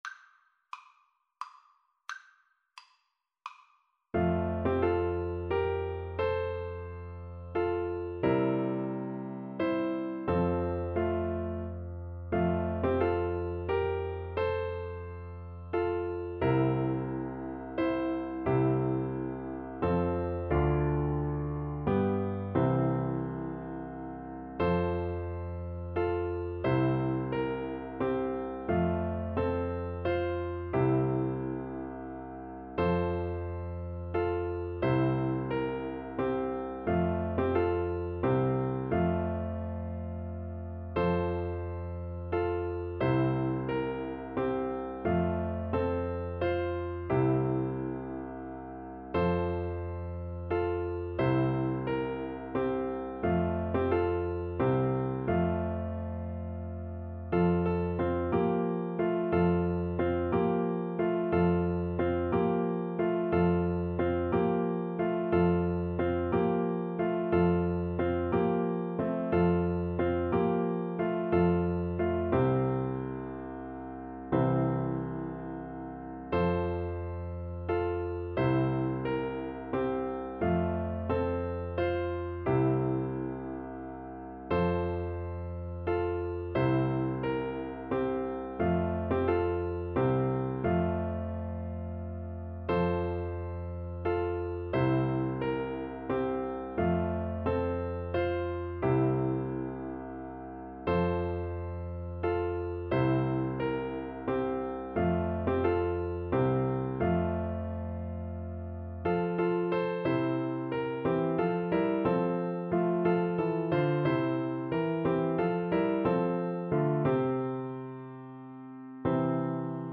Andante =c.88
3/4 (View more 3/4 Music)